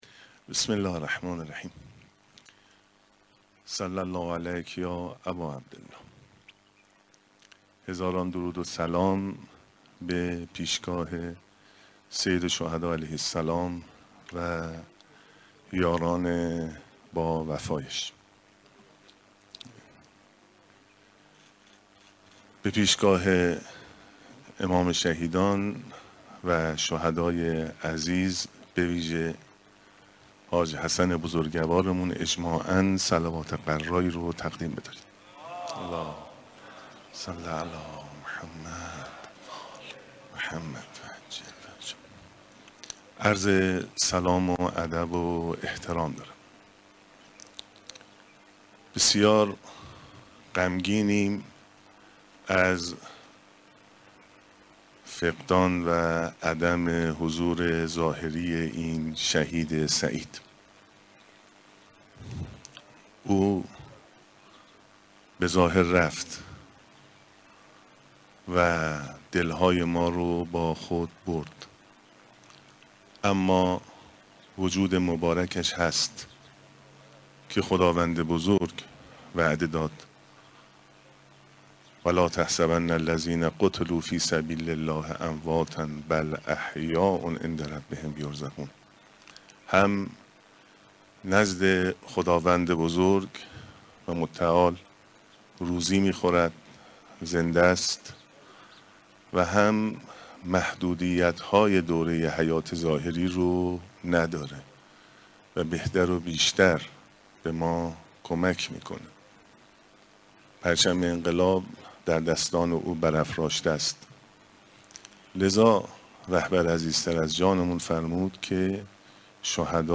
دوازدهمین سالگرد سردار شهید حاج حسن طهرانی مقدم پدر موشکی ایران در جوار مزارش در گلزار شهدای بهشت زهرا برگزار شد.
علیرضا زاکانی شهردار تهران در این مراسم دقایقی به سخنرانی پرداخت و گفت: شهید طهرانی مقدم به ظاهر از میان ما رفت ولی وجود مبارکش هست و در نزد خدا روزی می‌خورد و محدودیت حیات دنیایی را ندارد و بهتر و بیشتر به ما کمک می‌کند.